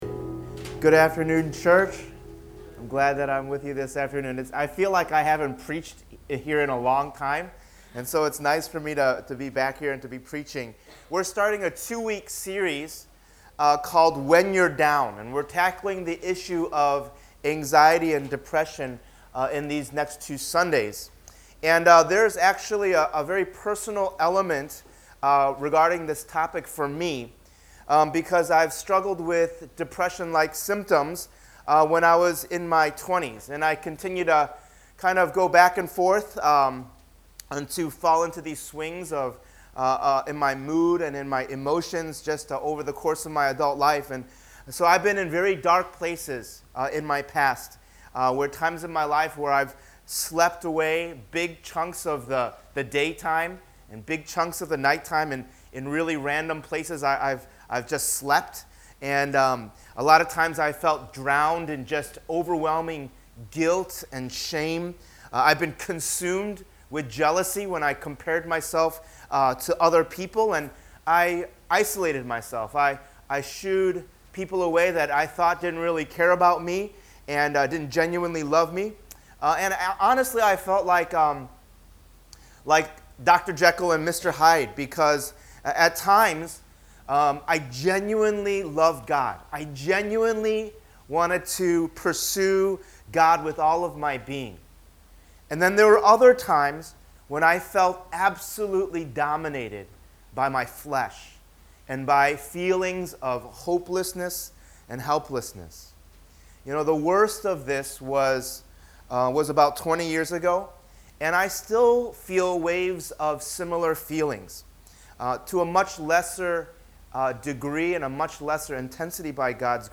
“When You’re Down” is a two-part sermon that will present a biblical approach to understanding mental health, how to handle depression and anxiety in one’s own life, and how we as the church can help others who struggle as well.